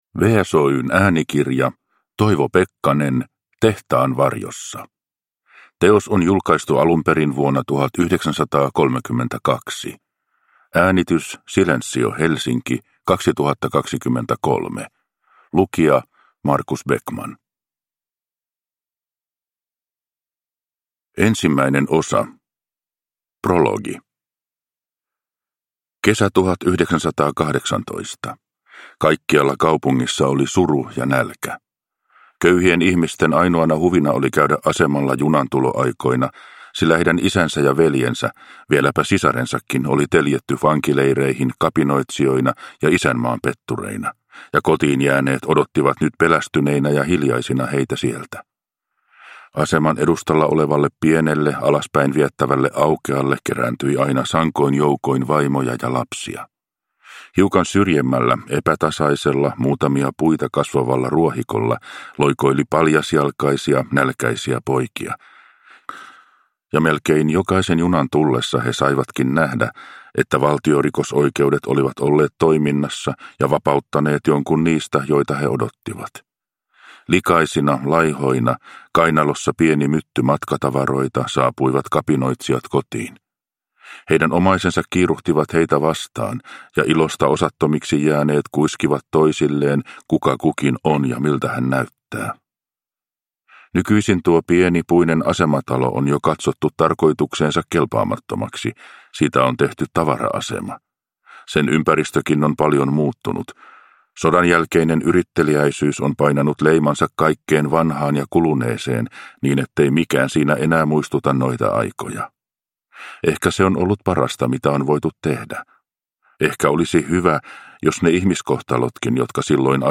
Tehtaan varjossa – Ljudbok – Laddas ner